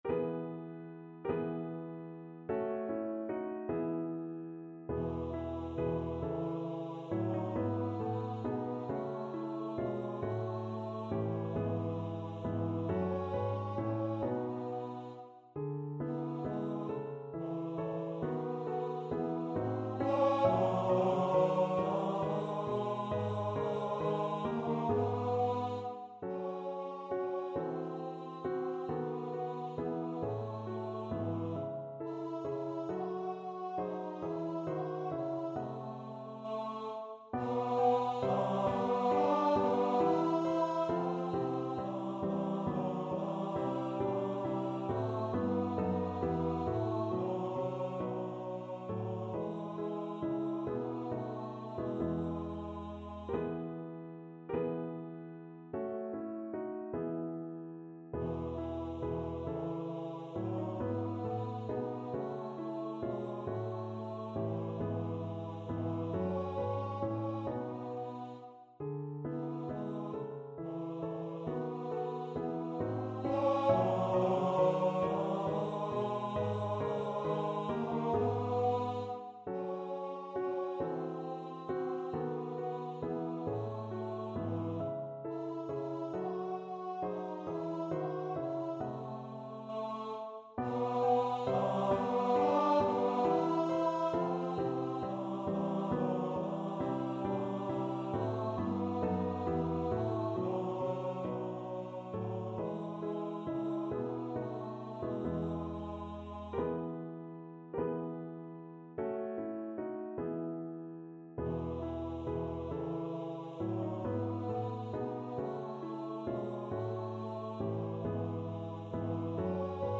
Free Sheet music for Choir (SATB)
3/8 (View more 3/8 Music)
Allegretto (. = 50)
Classical (View more Classical Choir Music)